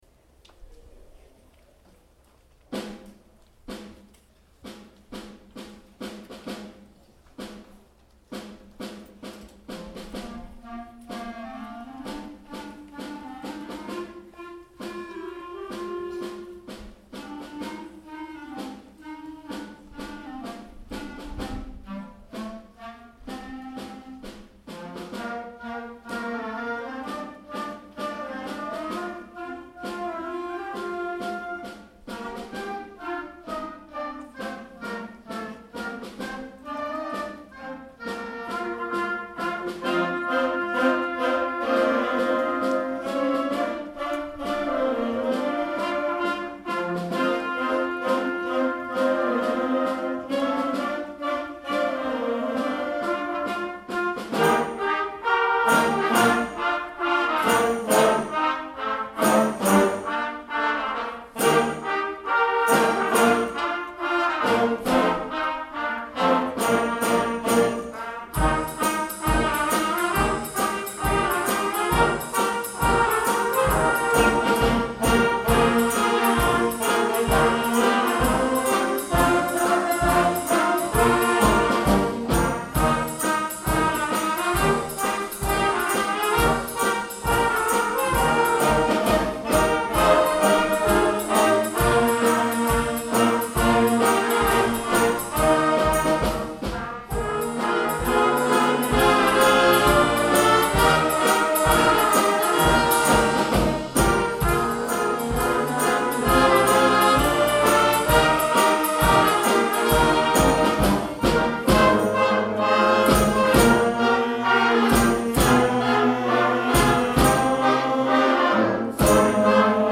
Junior Wind Band Nov 2016